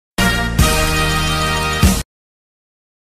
Tusch 1x.mp3